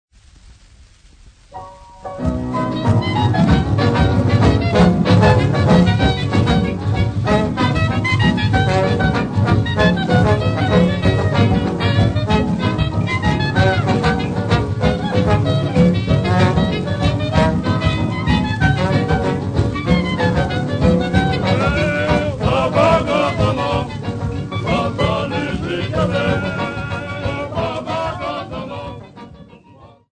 The Merry Black Birds Orchestra
Folk Music
Field recordings
Africa South Africa Johannesburg f-sa
sound recording-musical
Indigenous music